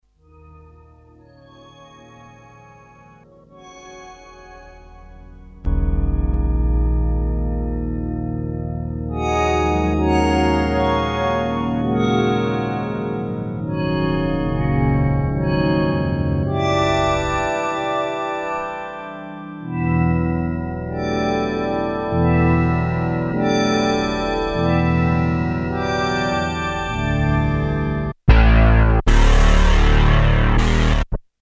demo mystic vector
Class: Synthesizer